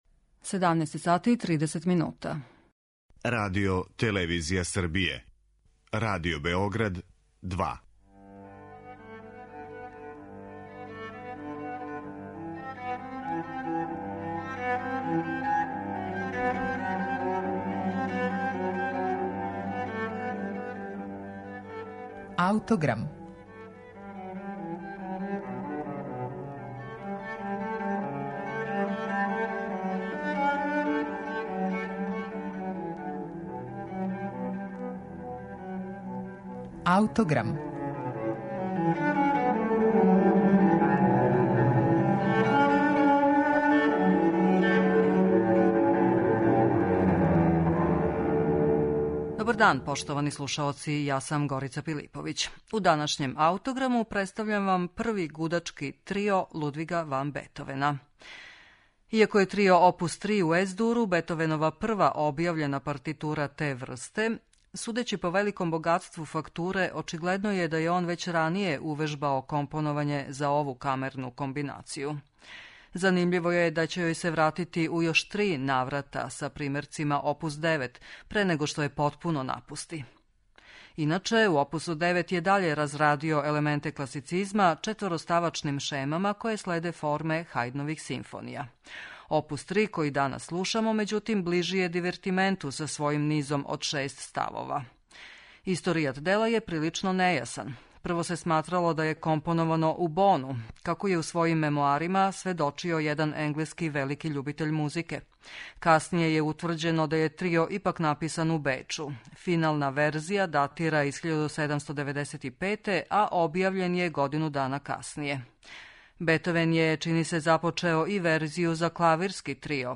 Гудачки трио опус 3 у Ес-дуру је прва објављена партитура те врсте Лудвига ван Бетовена.